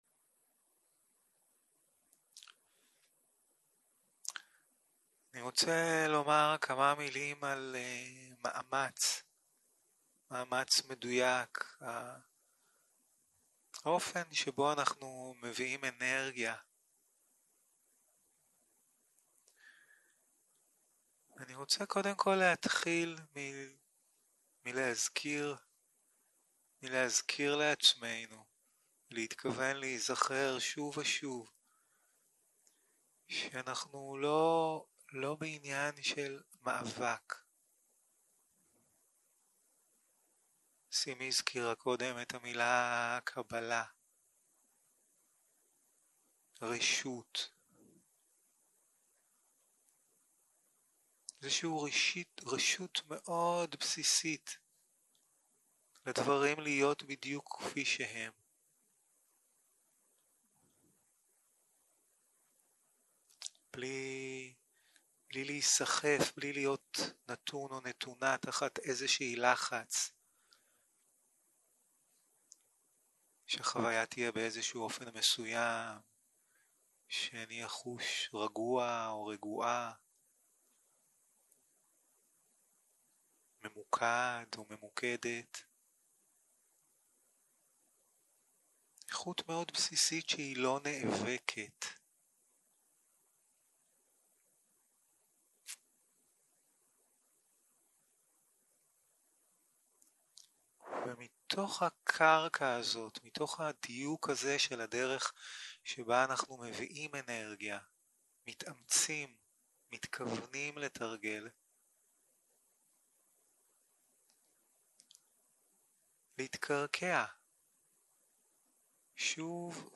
יום 1 - הקלטה 1 - ערב - מדיטציה מונחית - מאמץ נכון